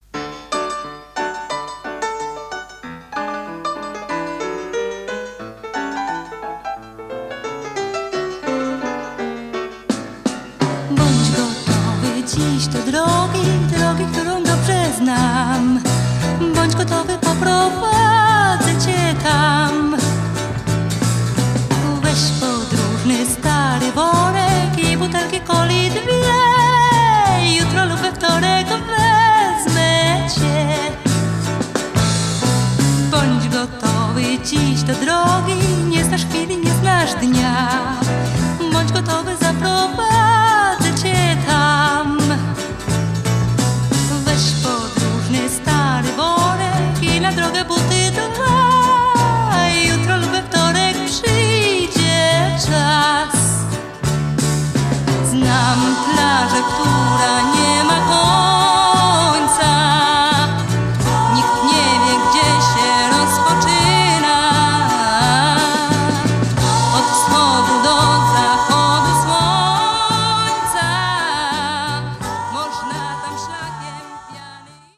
VOC GUITAR KEYB BASS DRUMS TEKST